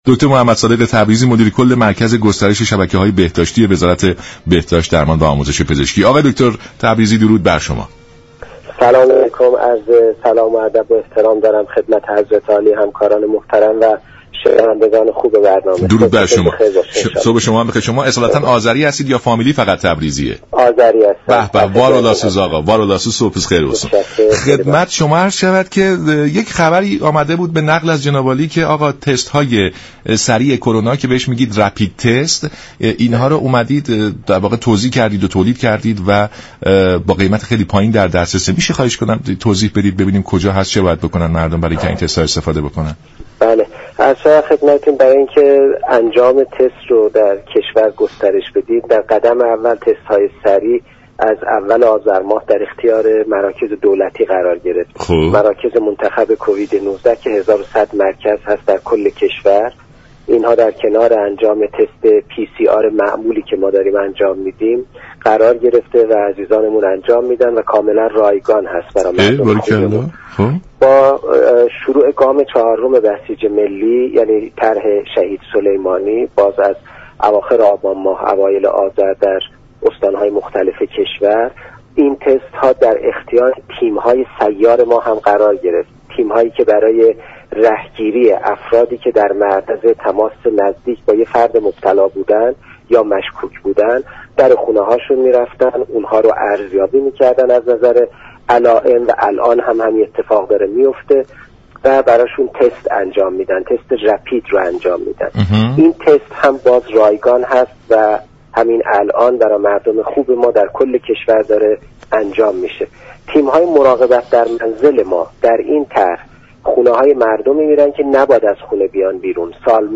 به گزارش شبكه رادیویی ایران، دكتر محمد صادق تبریزی مدیر كل مركز گسترش شبكه های بهداشتی وزارت بهداشت، درمان و آموزش پزشكی در برنامه سلام صبح بخیر درباره جزئیات توزیع و تولید تست های سریع كرونا گفت: تست های سریع كرونا از اول آذر ماه 99 در اختیار مراكز منتخب بیماری كووید 19 قرار گرفته است.